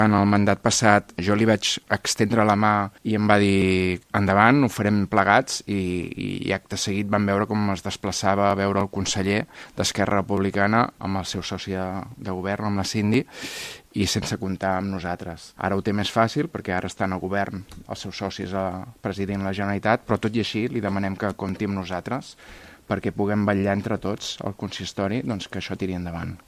Ahir, el portaveu municipal d’ERC, Xavier Ponsdomènech, retreia a l’alcalde que reclami unitat però no la practiqui.